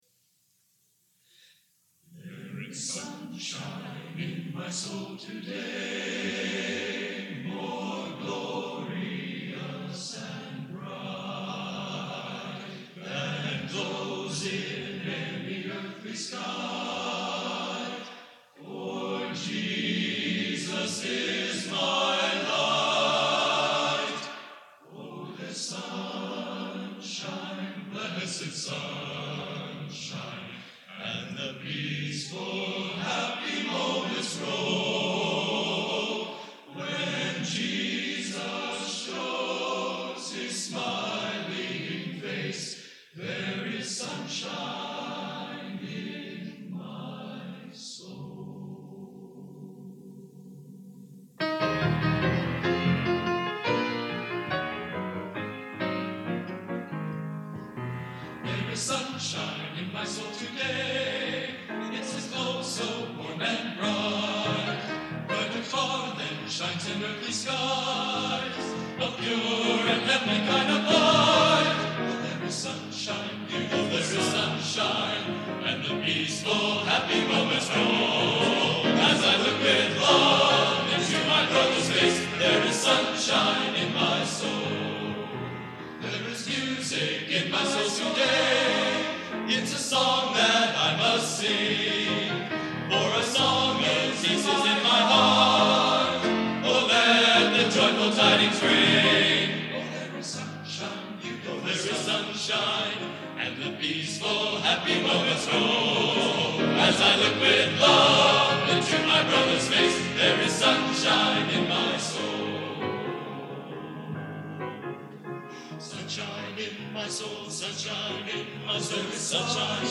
Genre: Gospel Sacred | Type: